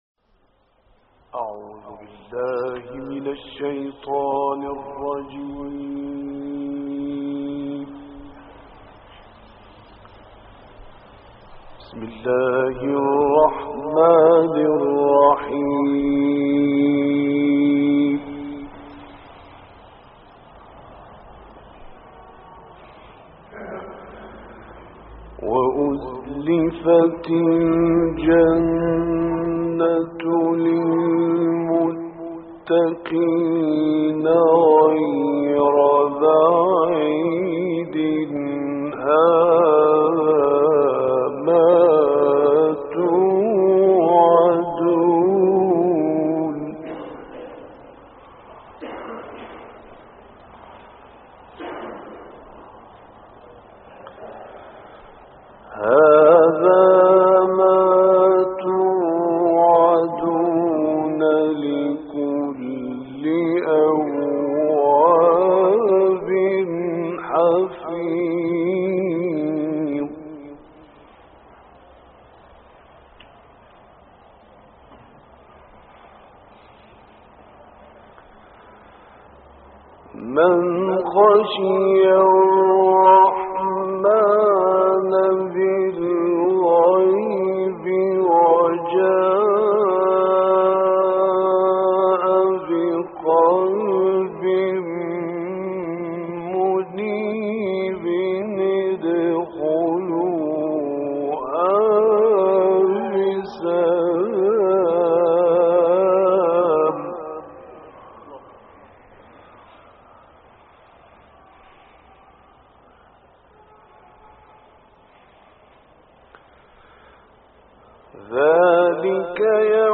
دانلود قرائت سوره های ق آیات 31 تا آخر ، نجم 53 تا آخر و غافر 1 تا 12 - استاد راغب مصطفی غلوش